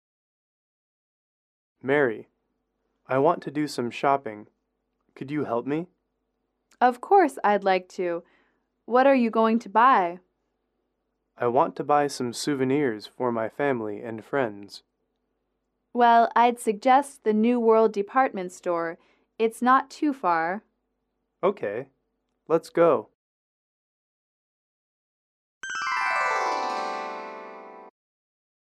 英语口语情景短对话17-3：应聘工作（MP3）